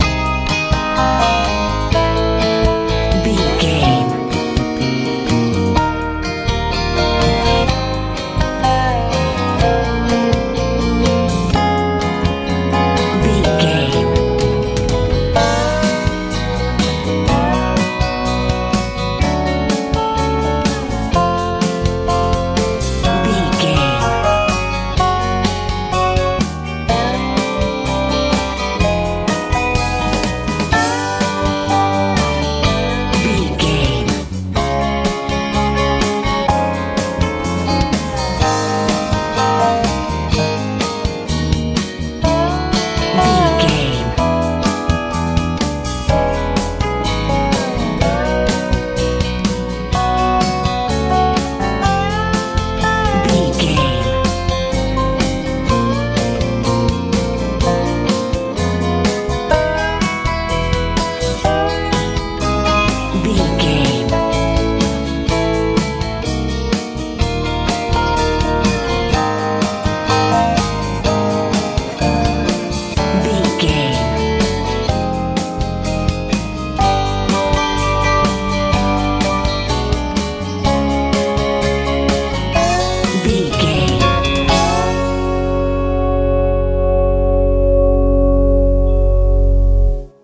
country pop feel
Ionian/Major
A♭
joyful
happy
drums
bass guitar
acoustic guitar
electric guitar
southern
bright